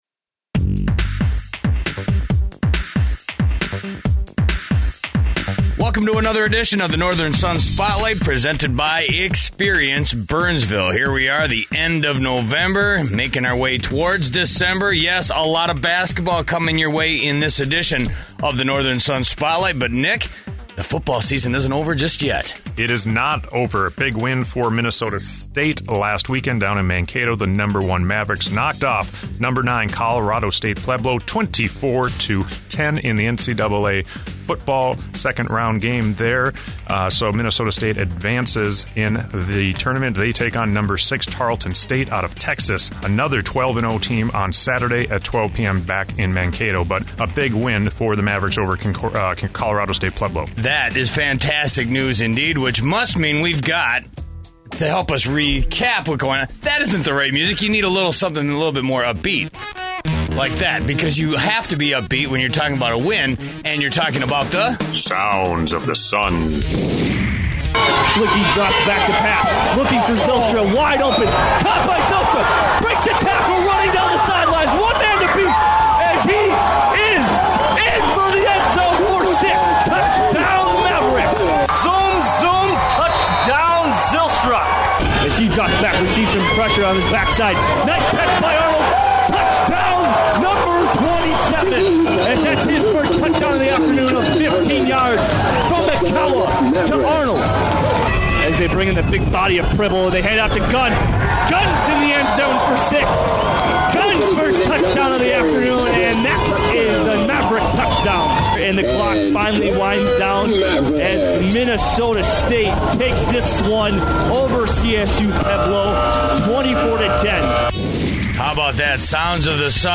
Catch the NSIC Spotlight over the airwaves in your community.